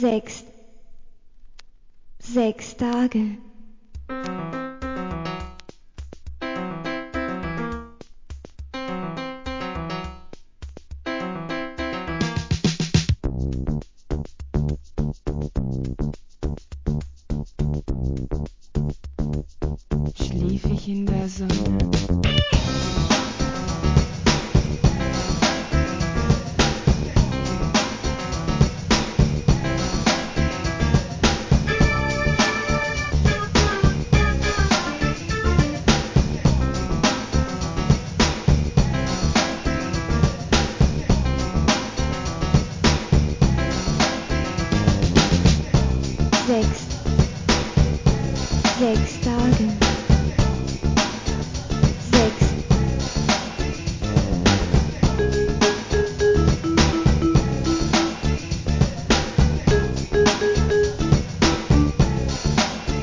HIP HOP/R&B
ラテン〜JAZZな洒落オツ・サウンド!!